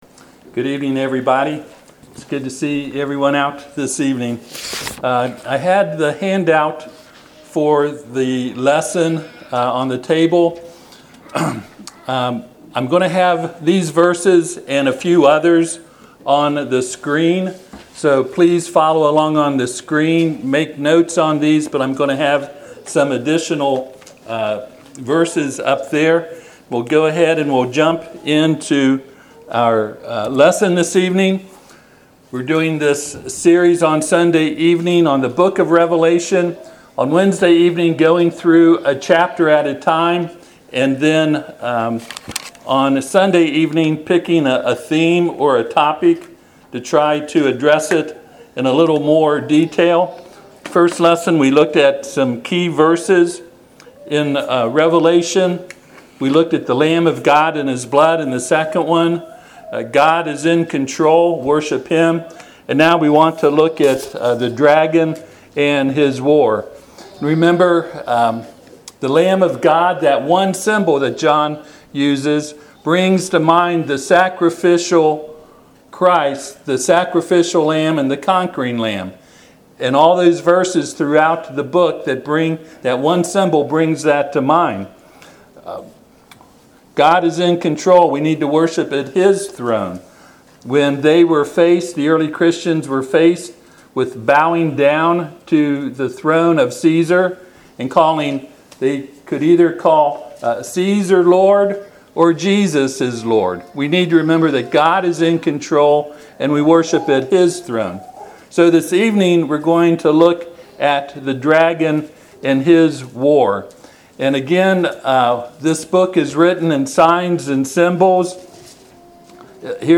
Passage: Revelation 12:7-9 Service Type: Sunday PM